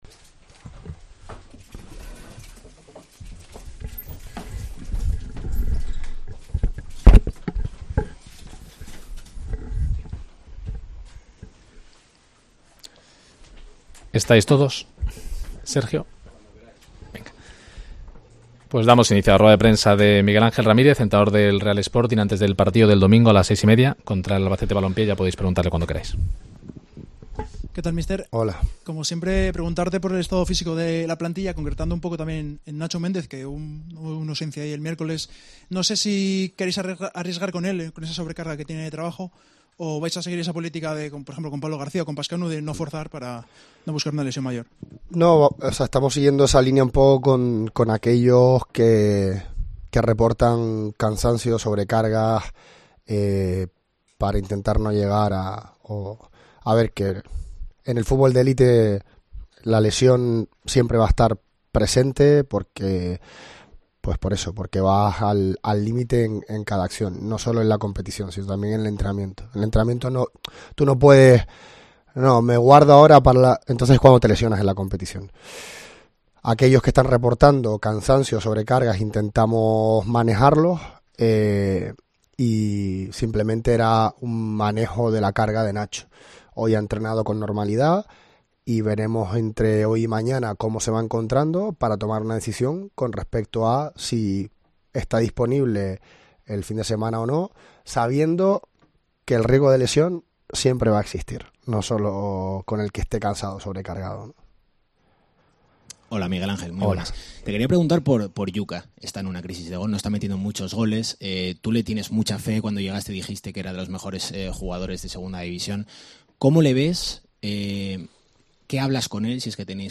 Rueda de prensa de Ramírez (previa Albacete - Sporting)